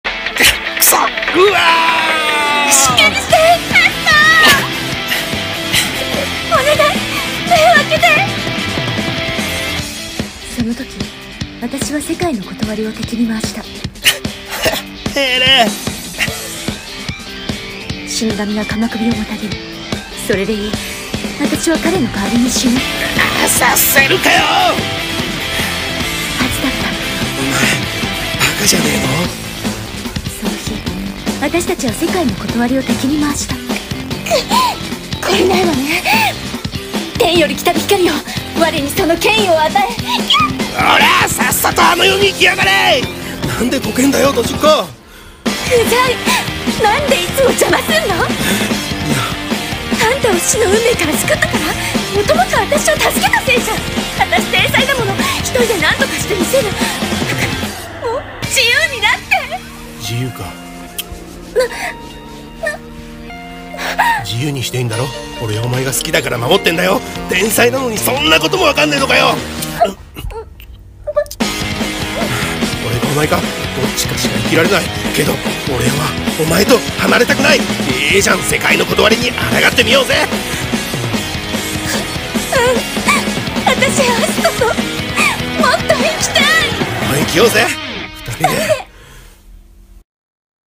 against【2人用声劇】